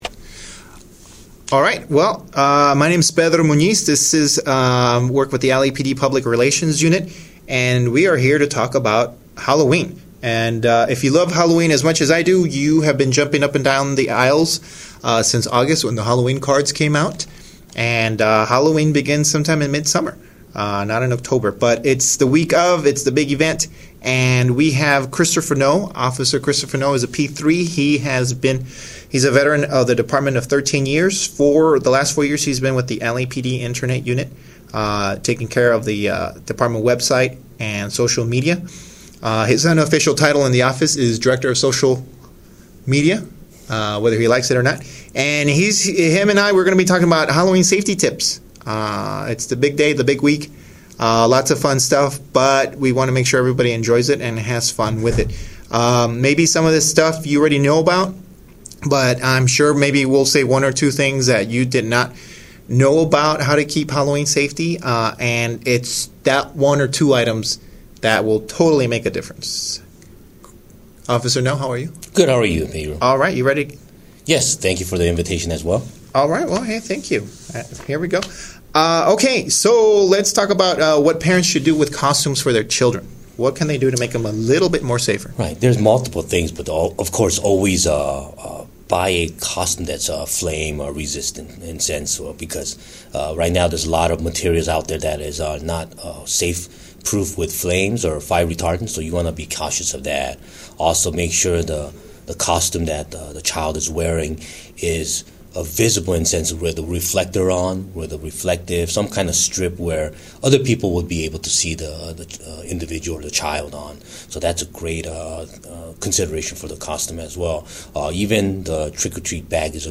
Discussion on Halloween Safety Tips